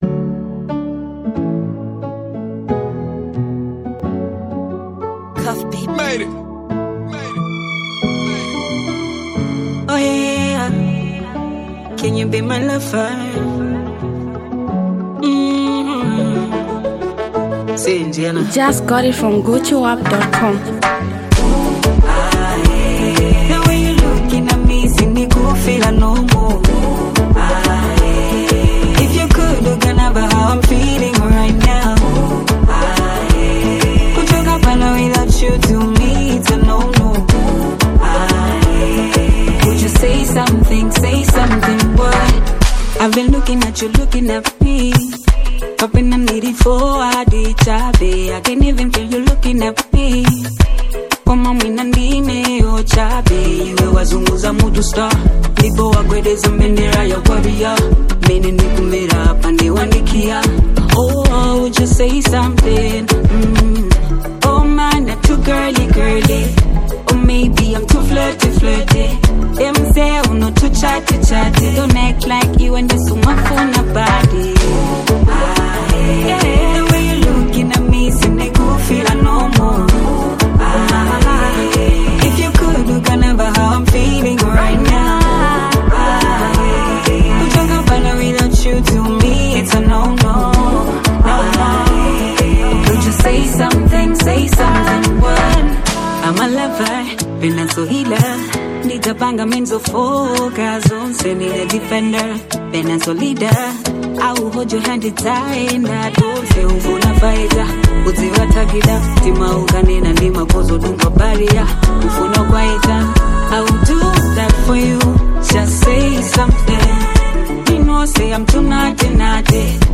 reggae and dancehall anthem
soulful voice